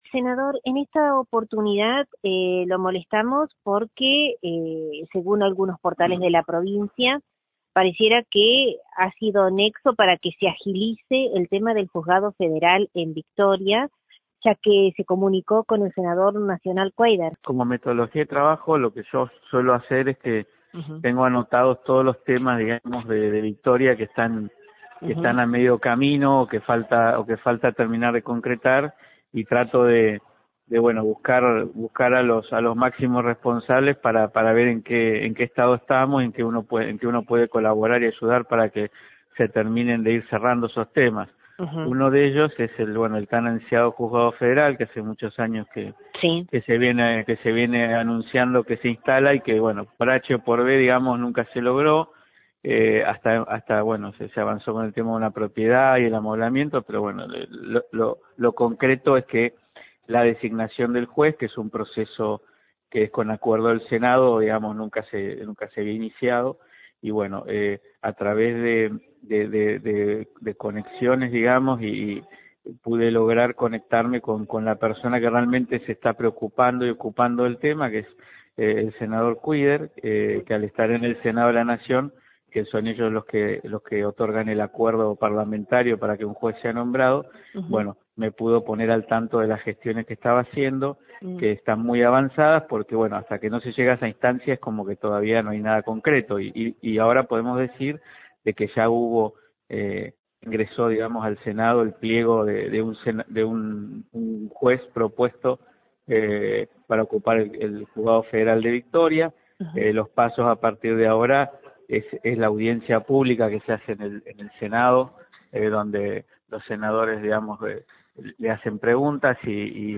En diálogo con LT39 NOTICIAS, el legislador provincial, anunció que sería inminente que antes de fin de año, ya esté la aprobación del Senado Nacional y la firma del Juez para la creación del mismo.